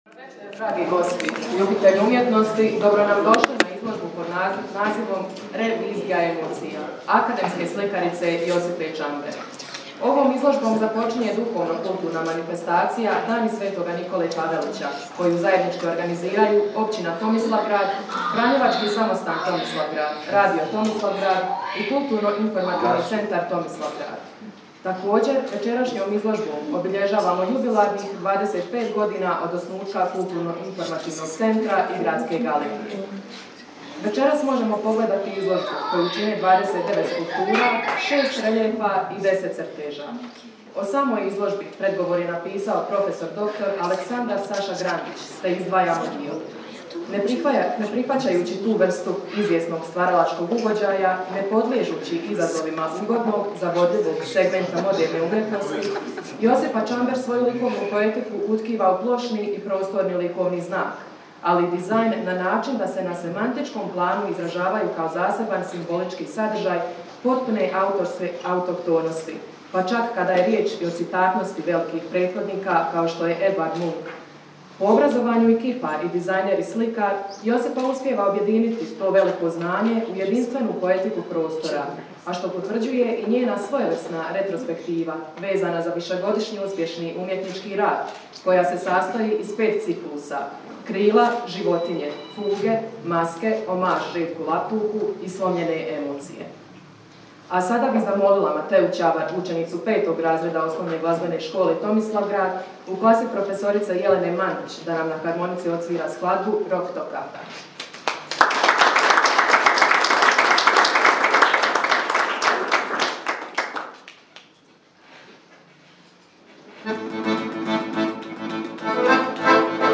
Čast otvorenja izložbe i Dana sv. Nikole Tavelića pripala je općinskom načelniku Ivanu Buntiću.
U zabavnom dijelu nastupili su učenici Srednje glazbene škole iz Tomislavgrada.